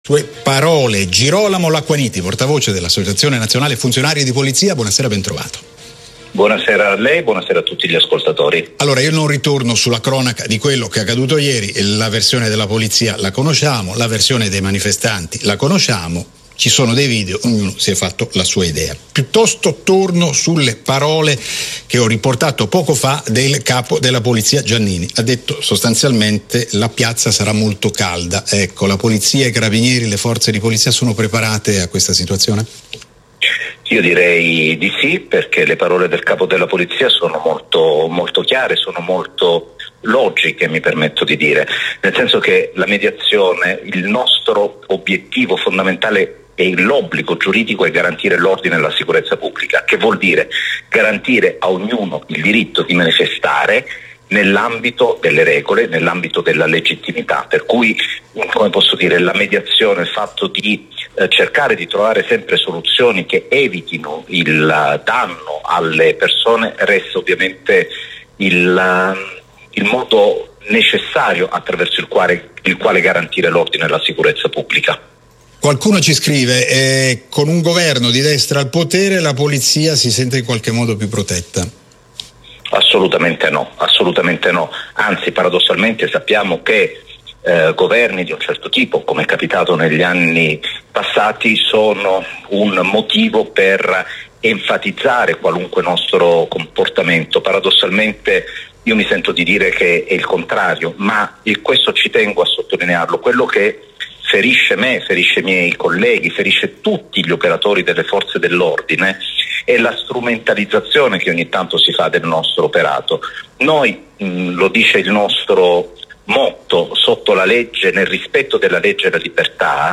Intervento a Radio 24